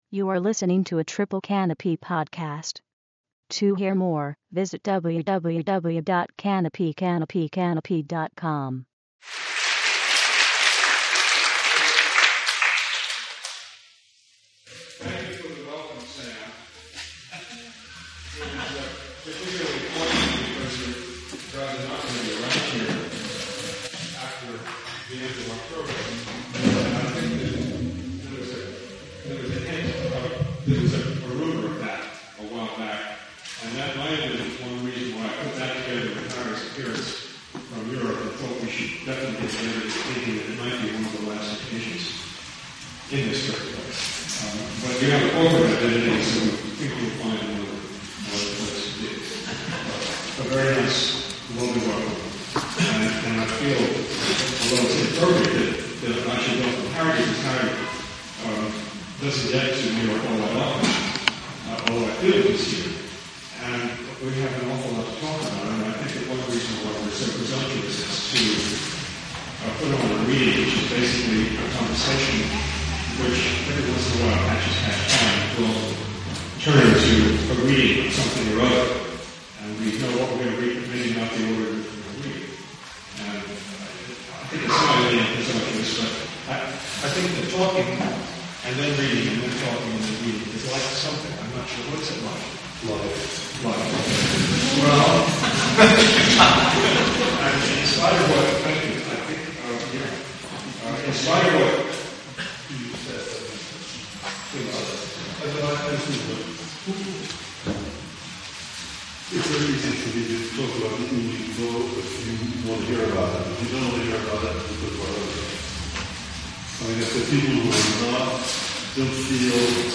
The obstruction, for Mathews and McElroy, being their microphones; now, for the listener, the degraded quality of this audio file, a recording of the two great American writers in conversation on October 21 at 177 Livingston, hosted by Triple Canopy.